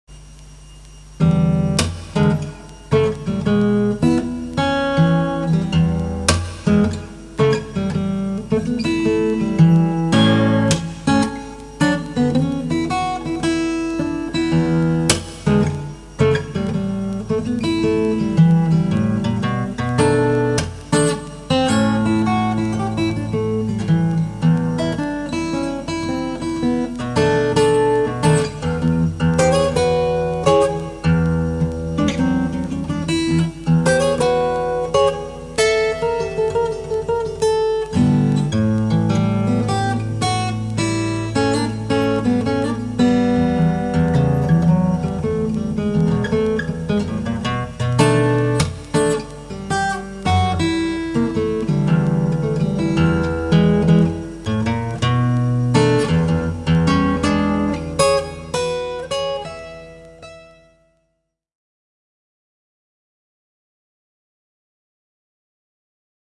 ぶるぅす（アコギ版）
やっぱJ-50の方が泥臭いフレーズはあいますなぁ。 ブルースフレーズ（アコギ版）.mp3 使用ギター：Gibson J-50
渋くていいですね～。
blues_aco.mp3